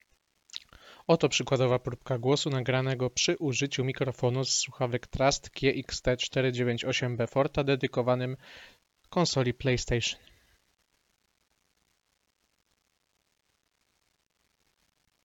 W ciemno zatem można by brać, że jakość dźwięku nagranego z tego mikrofonu nie będzie specjalnie zaskakująca. Tymczasem w praktyce wyszło nieco lepiej względem moich przypuszczeń.